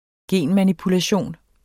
Udtale [ ˈgeˀnmanipulaˌɕoˀn ]